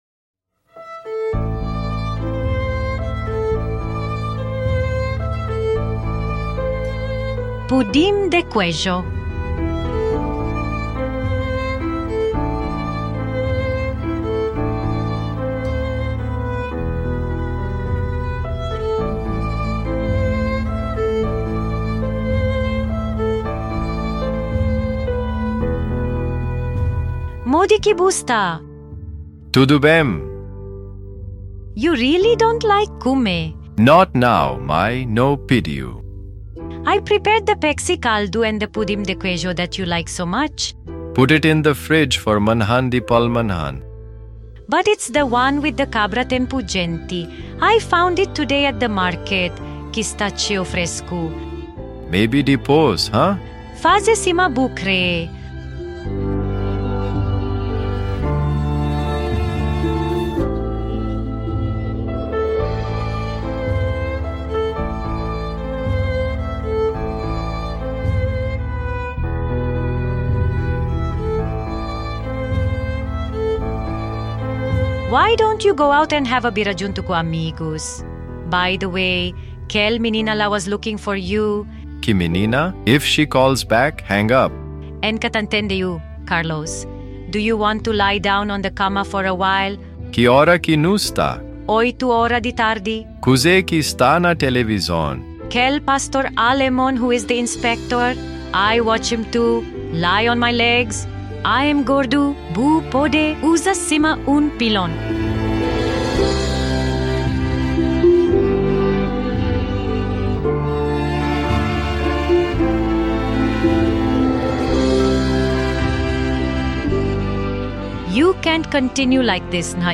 The soundtrack consists of a cover of "Cripple and the Starfish", a beautiful song by Antony and The Johnsons.